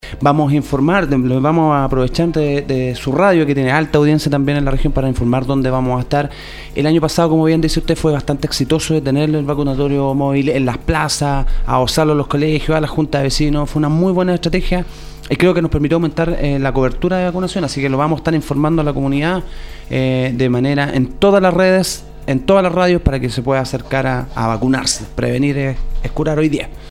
La mañana de este miércoles, en los estudios de Nostálgica, el Dr Claudio Baeza, director del Servicio de Salud Atacama, calificó el próximo invierno como un escenario complejo, tanto así que el propio Ministerio de Salud decidió adelantar la tradicional campaña de vacunación, la cual se adelantará para poder comenzar en el mes de marzo de 2020 y donde se extenderá la vacunación en los niños hasta los diez años, siendo que regularmente se hacía hasta los seis, proceso que se lleva a cabo en las escuelas y colegios de la región y el país.